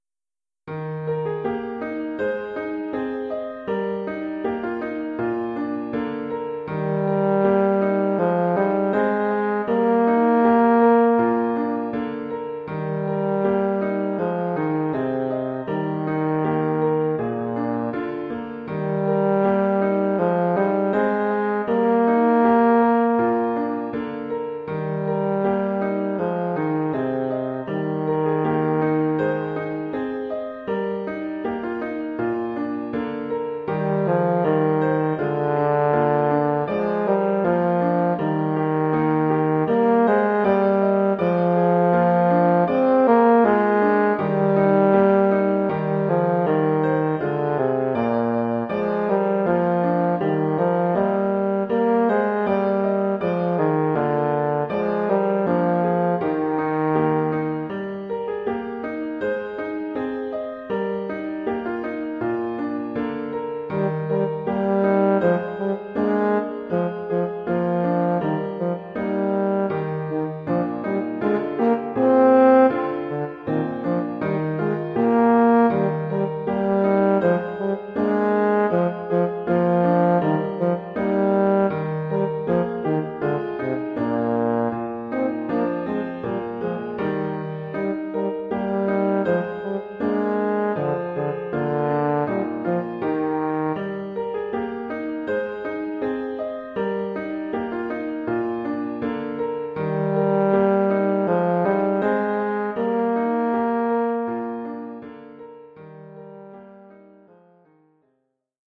Formule instrumentale : Saxhorn basse/Tuba et piano
Oeuvre pour saxhorn basse / euphonium /